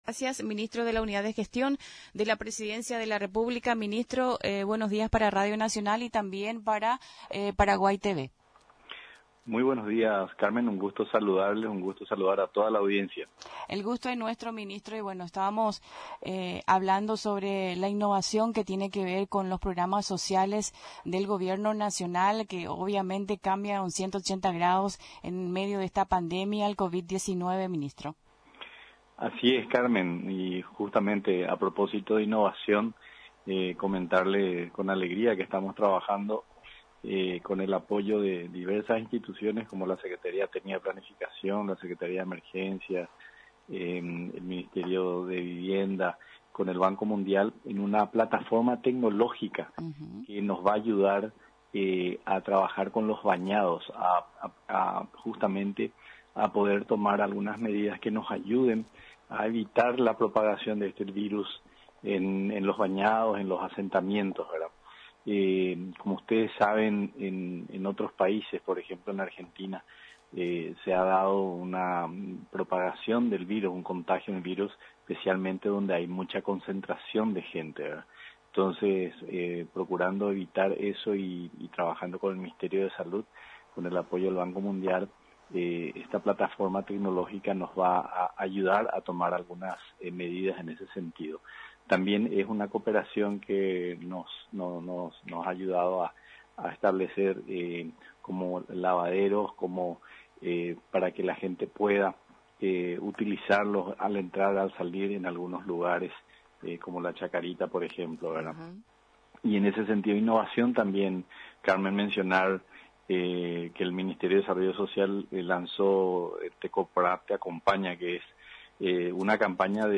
El ministro de Unidad de Gestión de la Presidencia, Hugo Cáceres, en contacto con Radio Nacional del Paraguay, afirmó que hay una innovación de los programas sociales por la Covid-19 , y que están trabajando con el apoyo de diversas instituciones como la Secretaría Técnica de Planificación , la Secretaría de Emergencia, el Ministerio de Vivienda y Hábitat y el Banco Mundial, a través de una plataforma tecnológica que va a ayudar a trabajar en la zona de los bañados , para tomar algunas medidas que permitan ayudar a evitar la propagación del coronavirus.